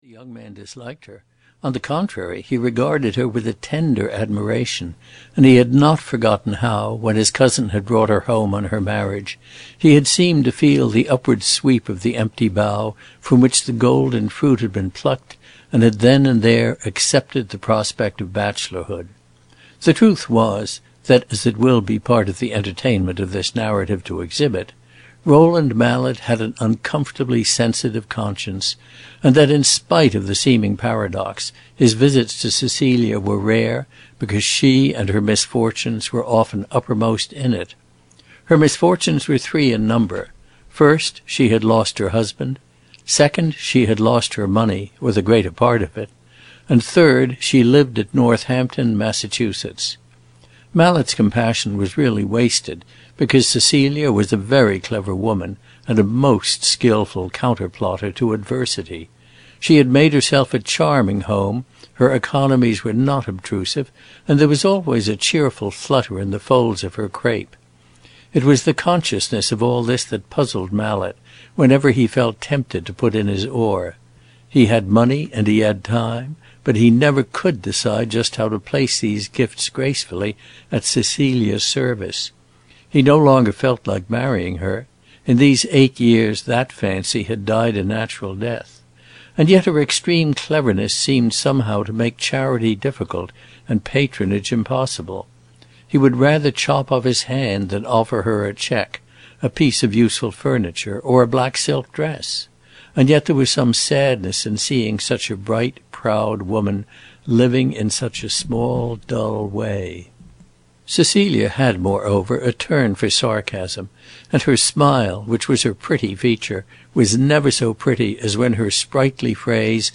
Roderick Hudson (EN) audiokniha
Ukázka z knihy